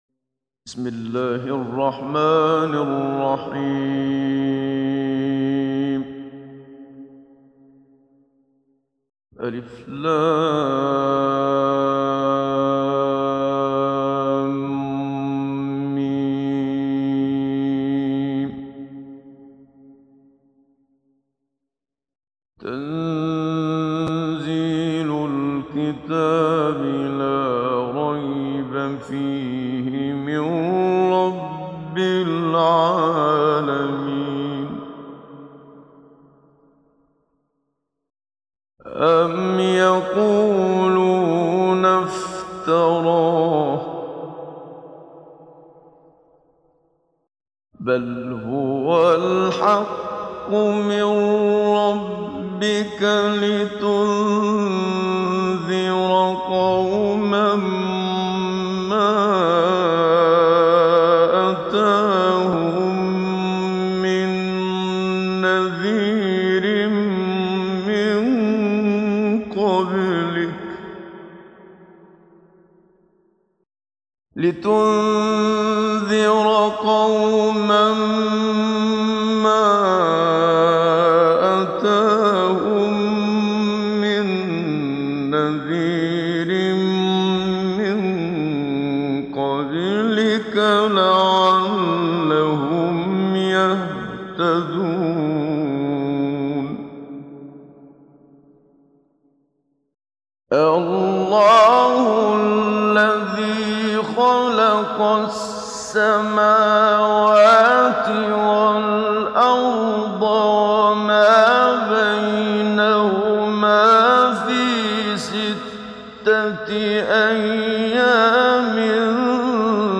ডাউনলোড সূরা আস-সাজদা Muhammad Siddiq Minshawi Mujawwad